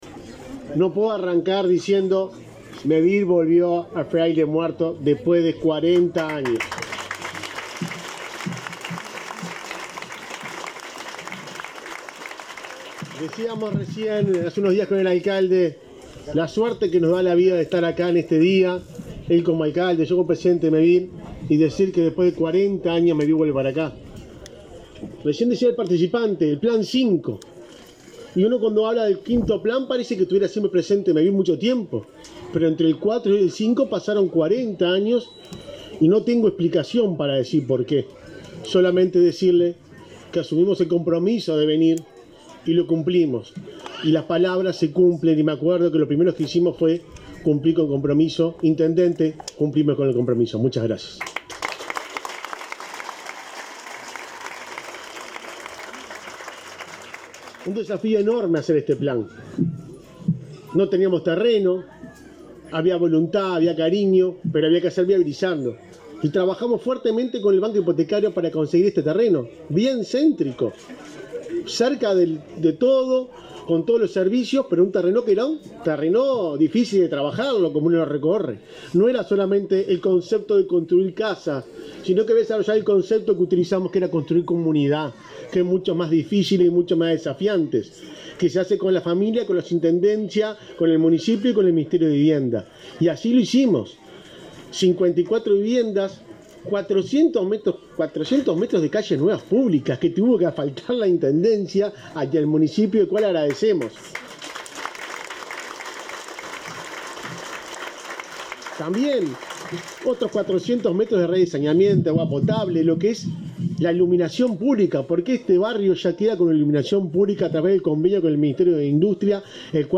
Palabras del presidente de Mevir, Juan Pablo Delgado
El presidente de Mevir, Juan Pablo Delgado, participó en el acto de inauguración de 54 viviendas de Mevir en la localidad de Fraile Muerto,